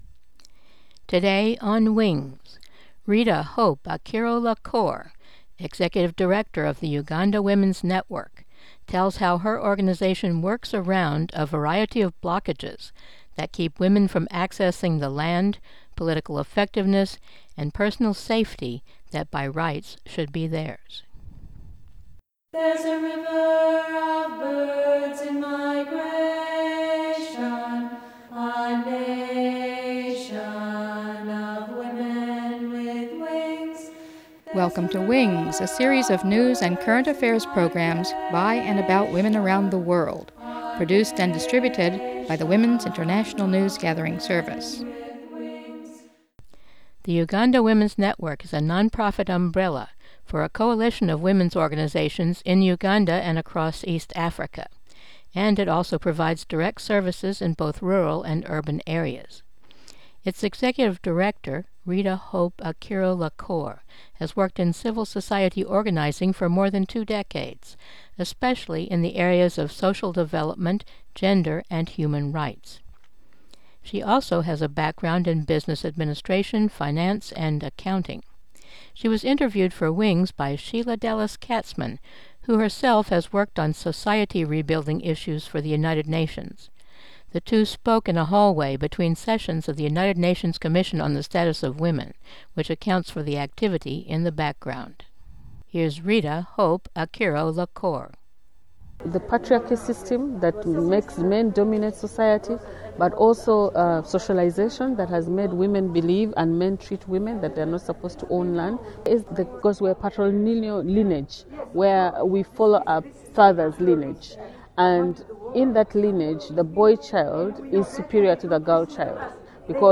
interviewed
at CSW 63, 2019, in New York Listen All